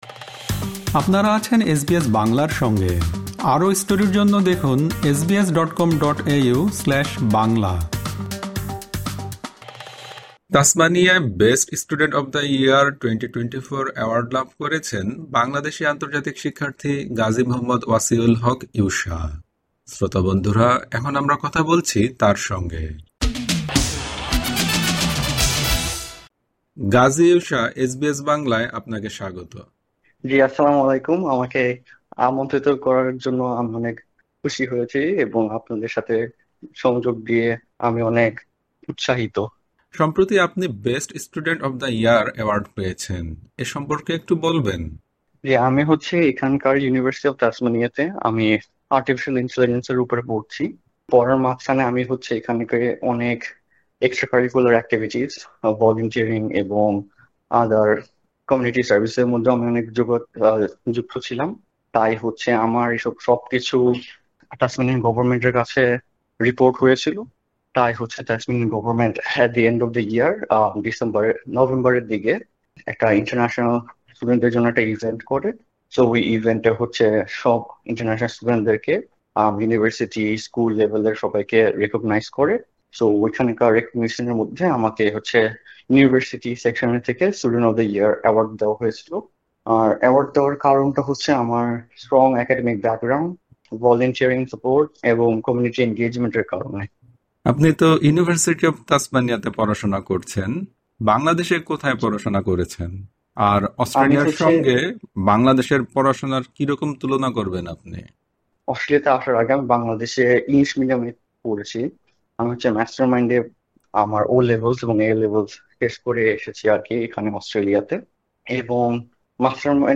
এসবিএস বাংলার সঙ্গে আলাপকালে বাংলাদেশের সঙ্গে এখানকার পড়াশোনার কয়েকটি দিক তুলনা করেছেন তিনি।